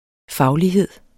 Udtale [ ˈfɑwliˌheðˀ ]